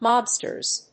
/ˈmɑbstɝz(米国英語), ˈmɑ:bstɜ:z(英国英語)/